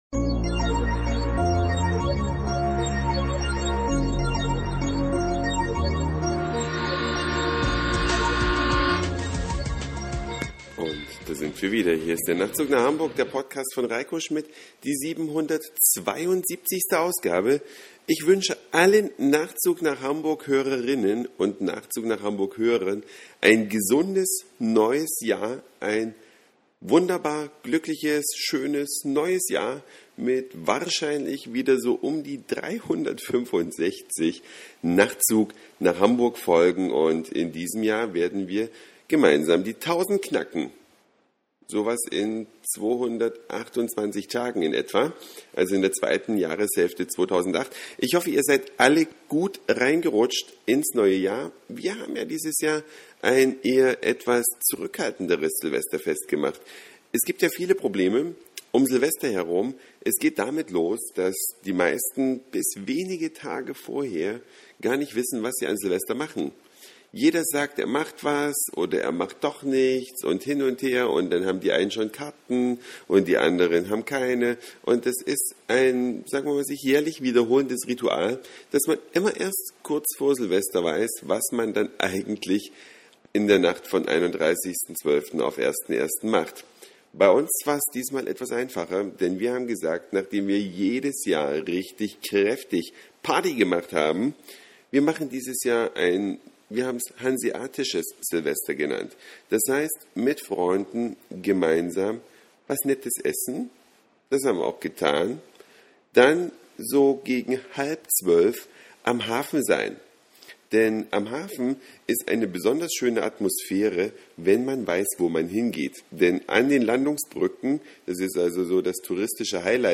Eine Reise durch die Vielfalt aus Satire, Informationen, Soundseeing und Audioblog.
Moët-Champagner an der Kehrwiederspitze, Schiffshörner im Hafen.